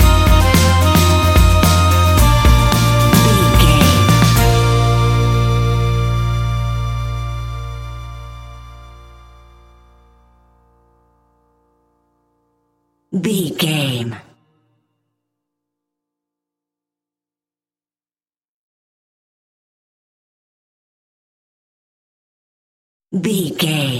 Uplifting
Ionian/Major
E♭
folk music
acoustic guitar
mandolin
ukulele
lapsteel
drums
double bass
accordion